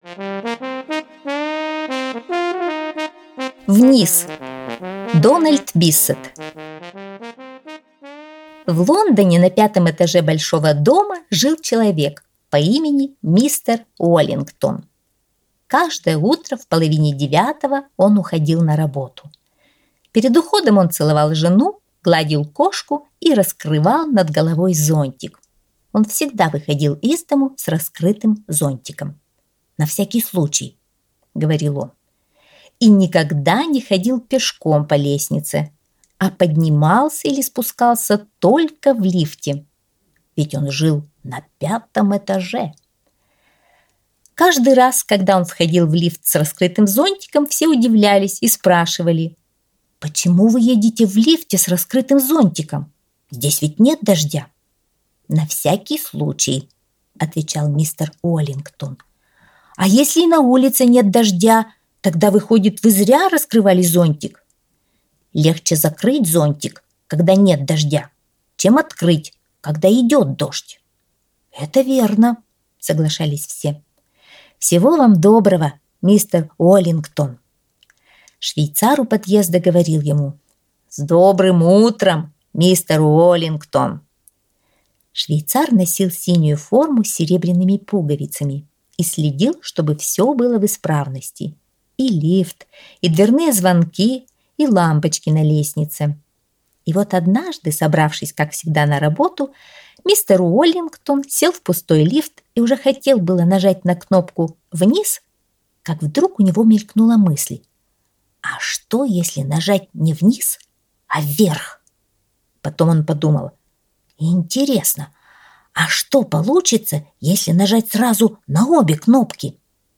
Вниз - аудиосказка Дональда Биссета - слушать онлайн | Мишкины книжки